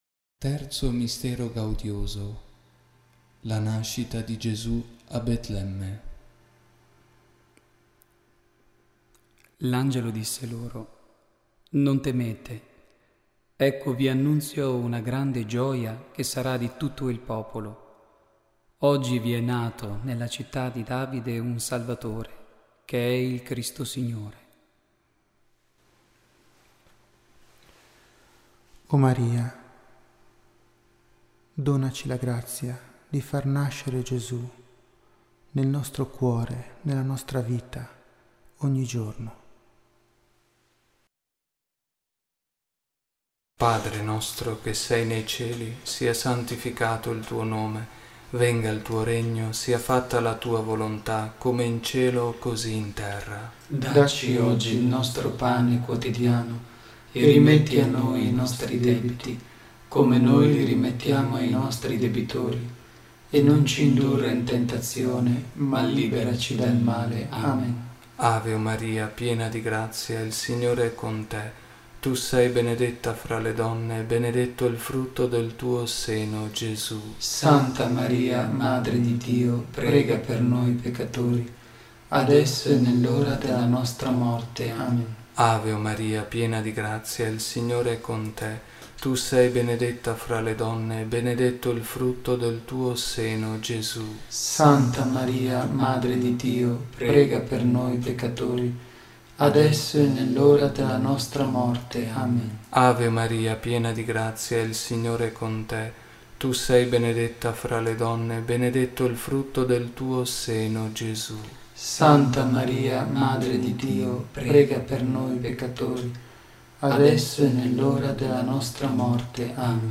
registrazione in studio
Il Santo Rosario in mp3